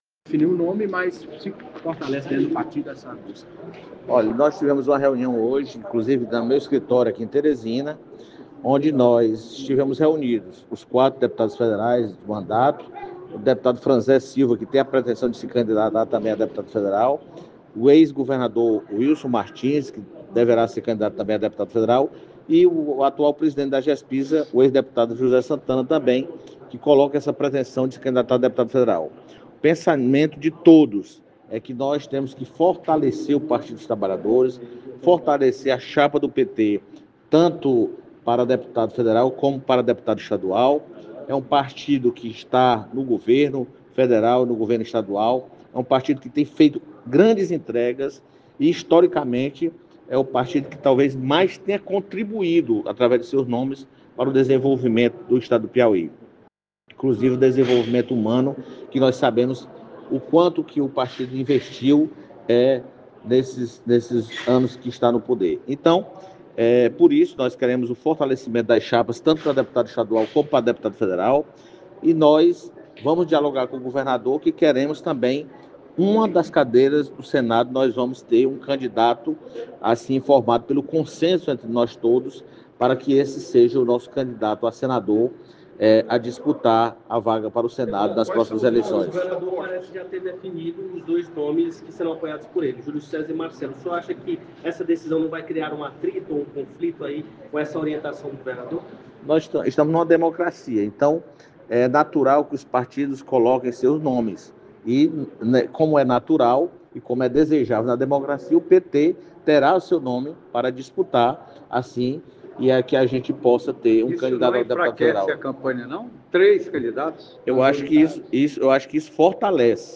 A seguir o áudio da entrevista do deputado federal Florentino Neto